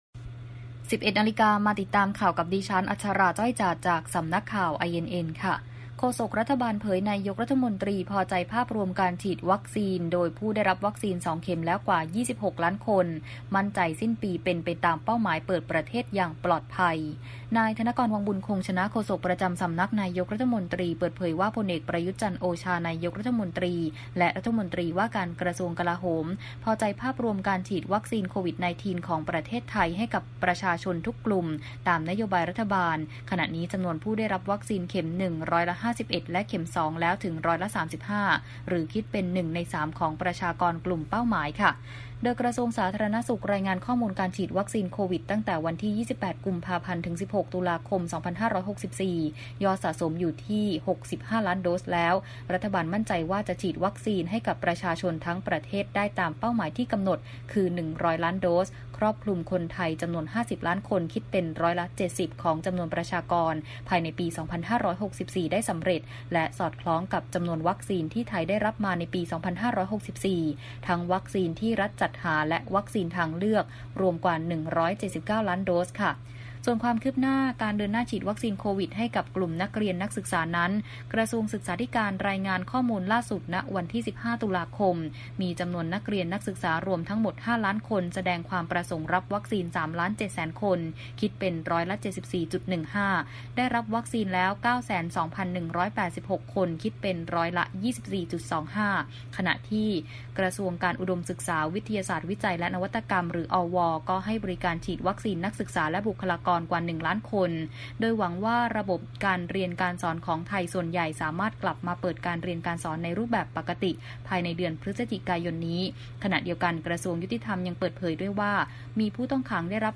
Video คลิปข่าวต้นชั่วโมง ข่าว